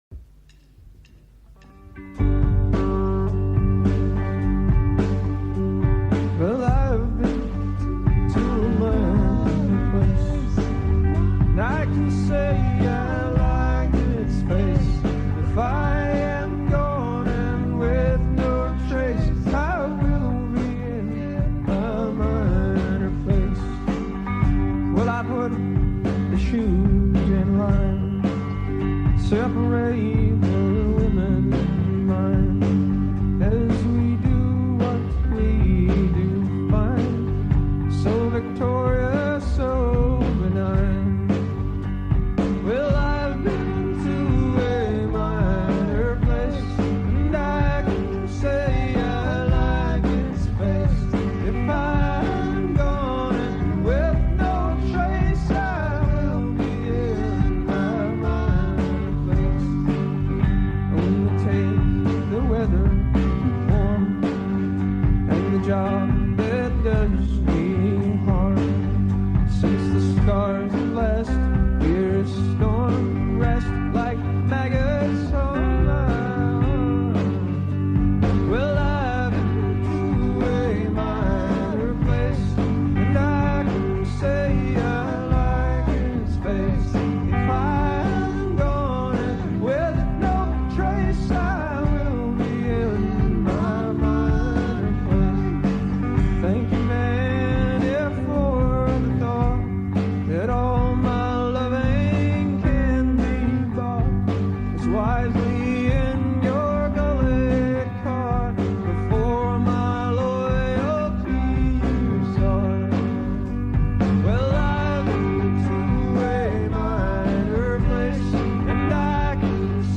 enregistrée le 26/01/1999  au Studio 105